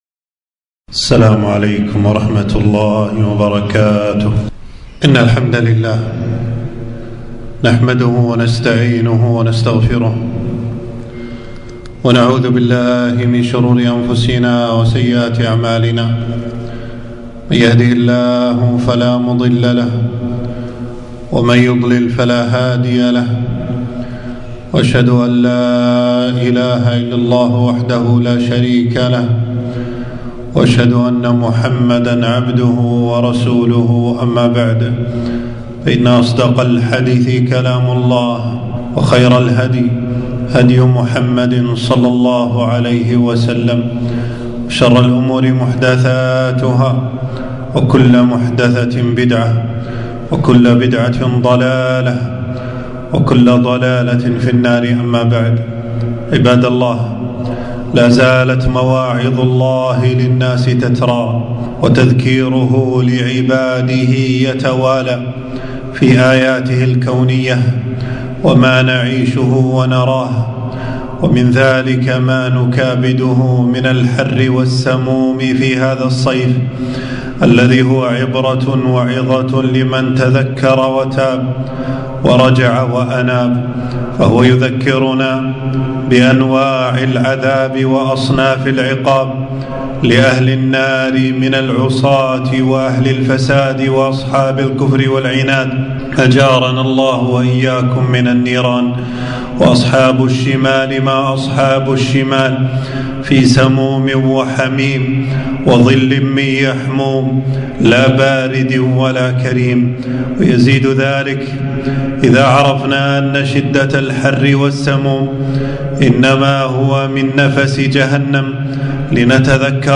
خطبة - النار وأهوالها وأصناف عذابها